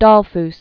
(dôlfs), Engelbert 1892-1934.